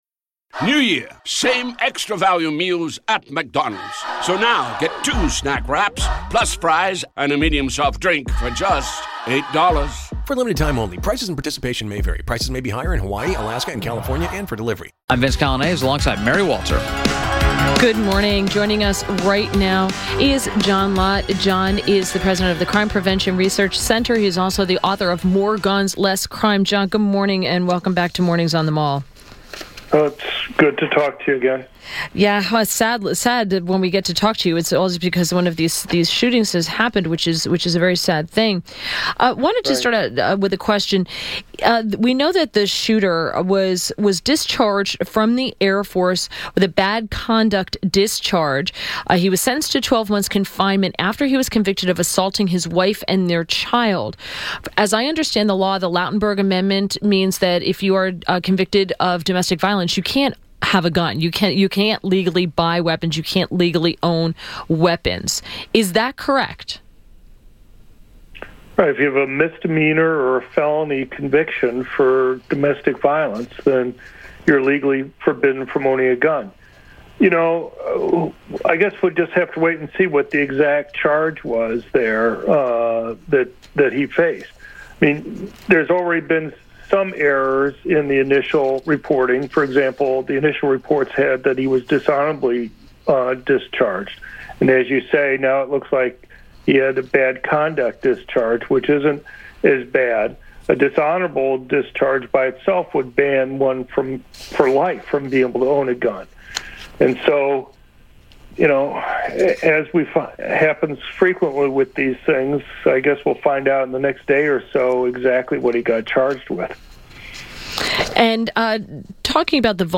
WMAL Interview - JOHN LOTT - 11.06.17
INTERVIEW — JOHN LOTT – President, Crime Prevention Research Center and author of “More Guns Less Gun” – shared his thoughts on the church shooting.